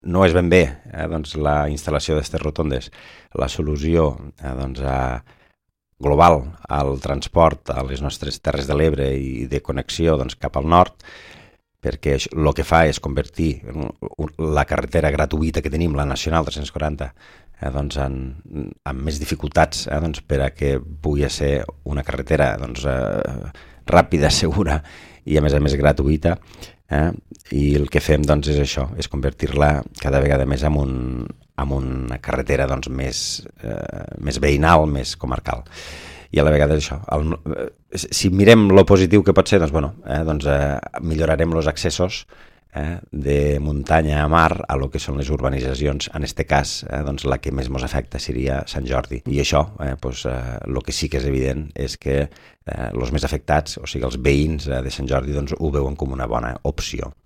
Jordi Gaseni és l’alcalde de l’Ametlla de Mar: